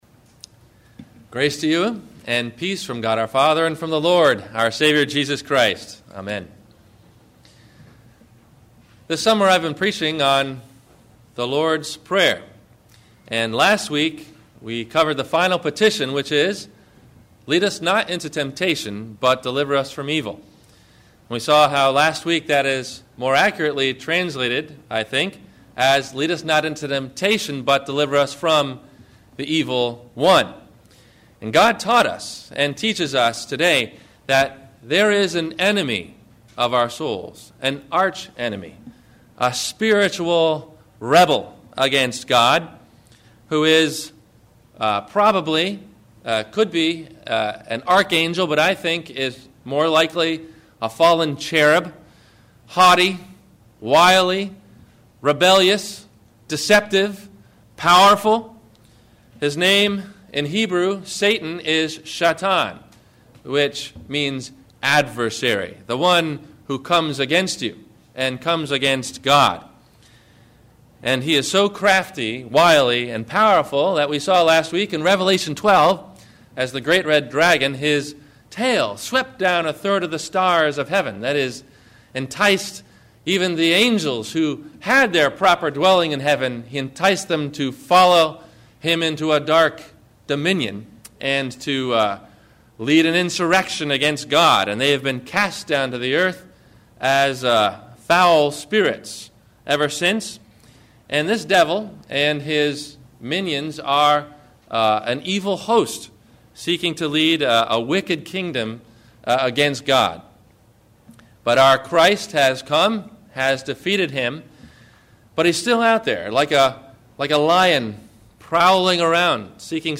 Joyful Prayer – Sermon – July 20 2008